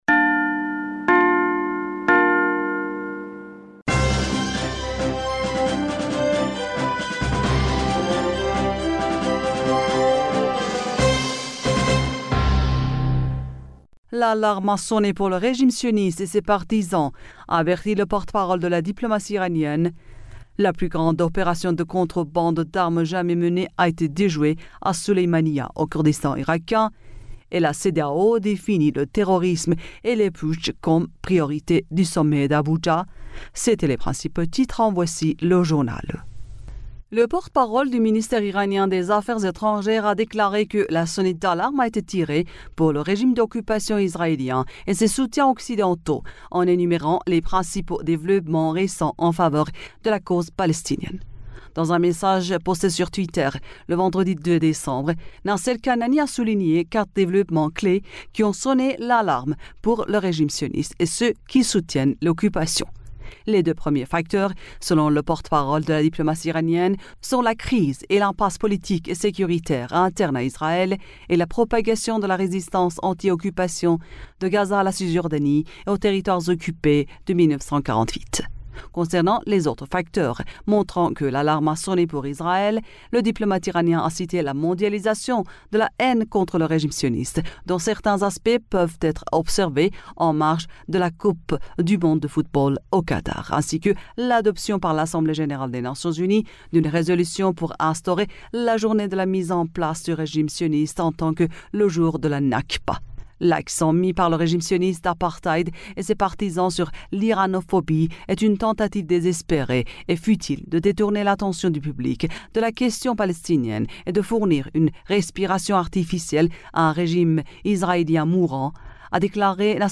Bulletin d'information du 03 Décembre